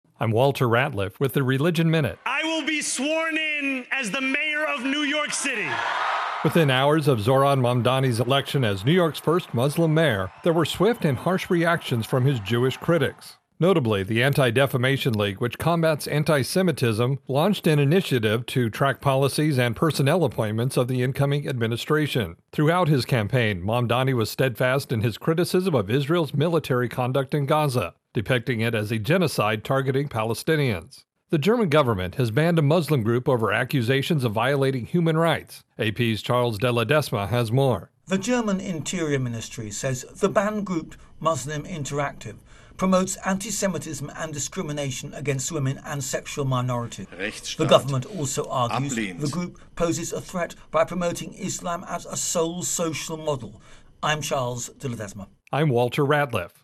AP Correspondent
Latest Stories from The Associated Press / On this week's AP Religion Minute, the German government bans a Muslim group, and Jewish reaction to the election of New York first Muslim mayor.